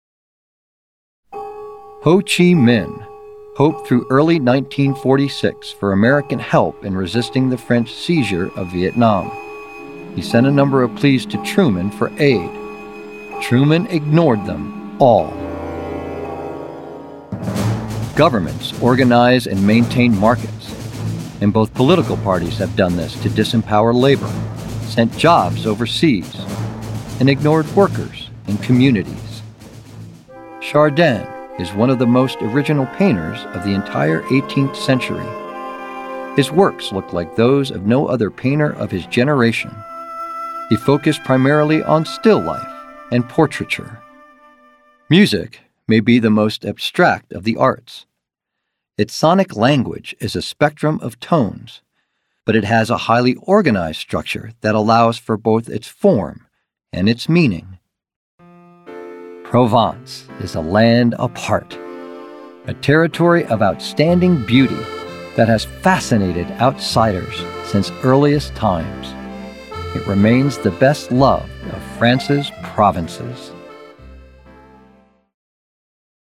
Narration
English - Midwestern U.S. English
Middle Aged
Senior